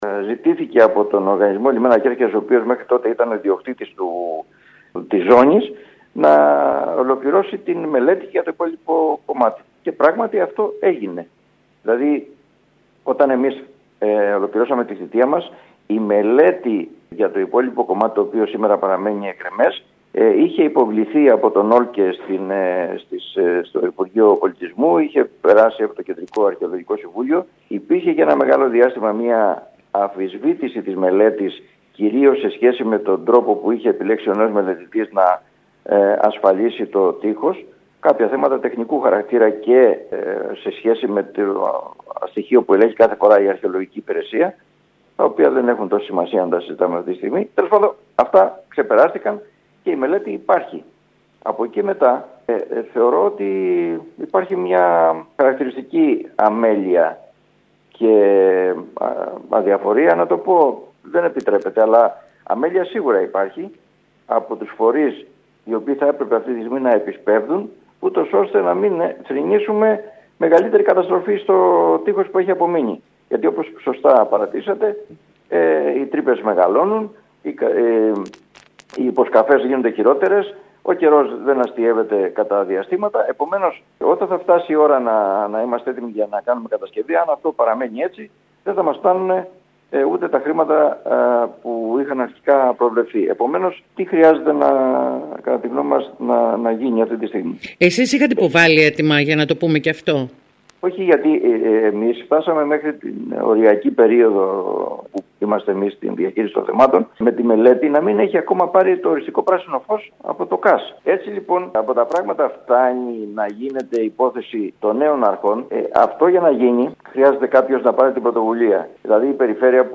Ο πρώην Περιφερειάρχης Θόδωρος Γαλιατσάτος δηλώνει σήμερα στο σταθμό μας για το θέμα αυτό ότι, στη λήξη της θητείας του ολοκληρώθηκε η μελέτη και ξεπεράστηκαν τα χρονοβόρα εμπόδια της αρχαιολογίας και της έγκρισης της από το Κεντρικό Αρχαιολογικό συμβούλιο.